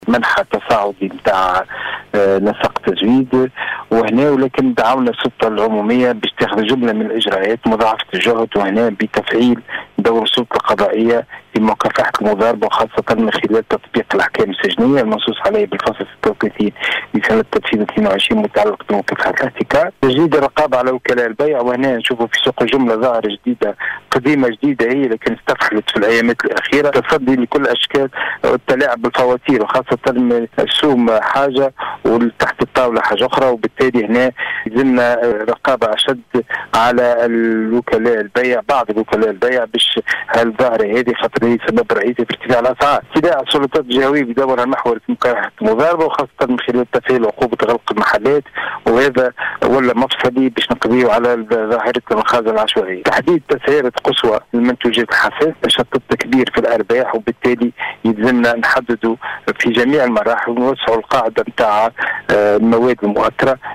يتحدث لأوليس اف ام.(تسجيل).